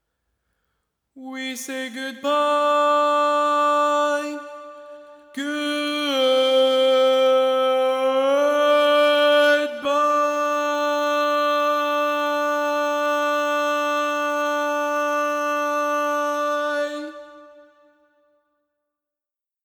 Type: Barbershop
Each recording below is single part only.
Learning tracks sung by